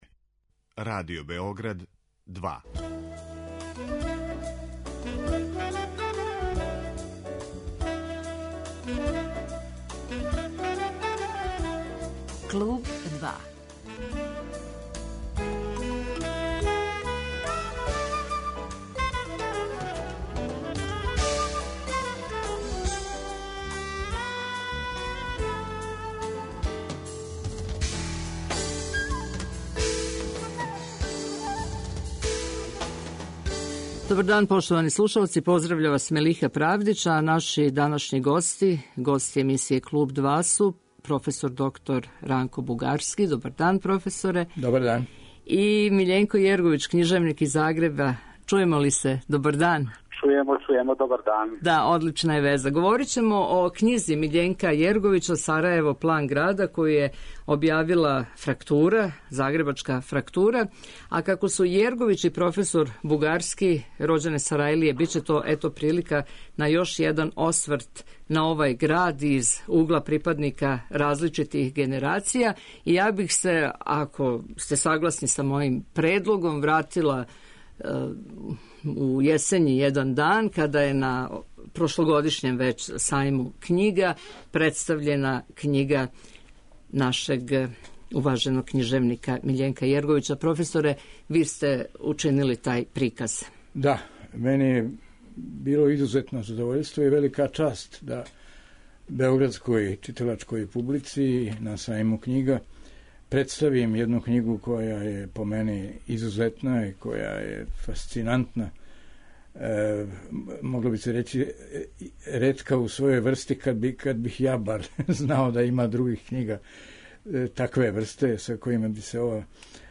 Гости емисије Клуб 2 су проф. др Ранко Бугарски и Миљенко Јеровић, књижевник из Загреба. Разговараћемо о књизи "Сарајево, план града".